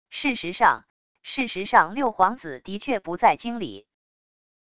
事实上……事实上六皇子的确不在京里wav音频生成系统WAV Audio Player